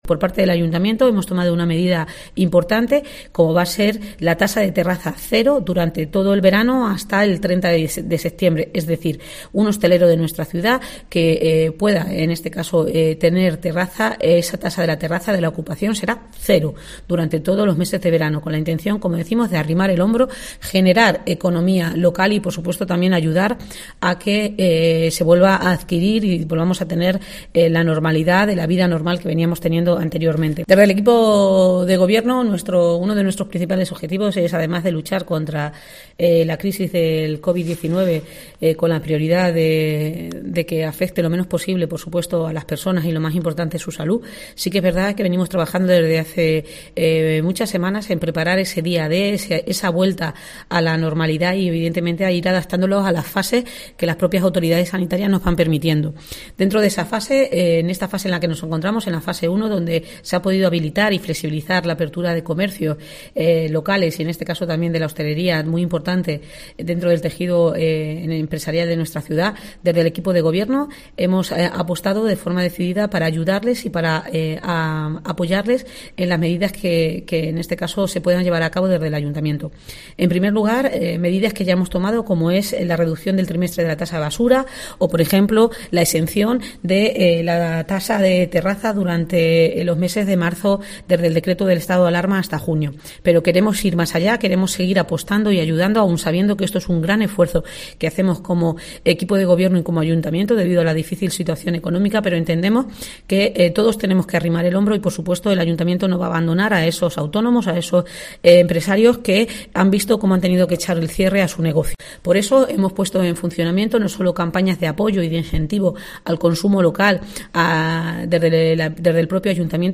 ctv-odn-declaraciones-alcaldesa-terrazas-y-pymes